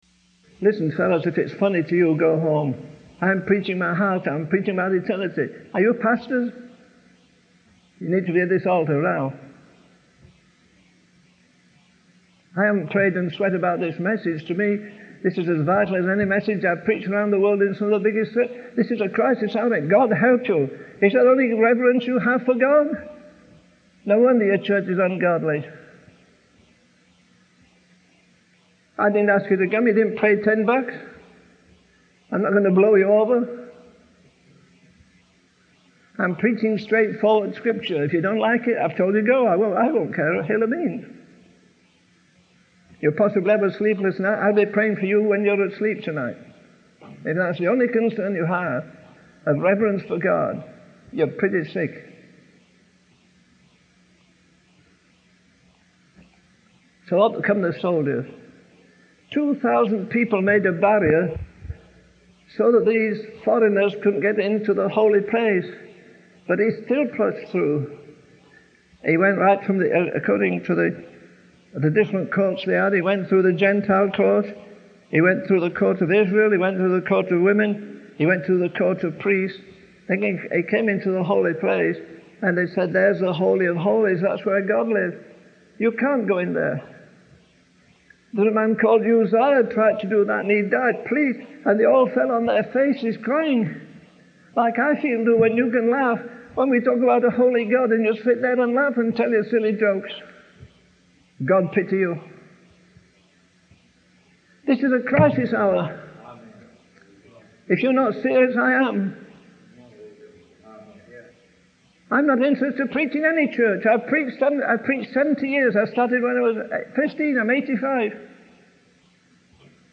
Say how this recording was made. In this sermon, the preacher emphasizes the importance of reverence for God and the seriousness of the message he is delivering. He challenges the pastors in the audience to truly listen and allow the message to impact their lives.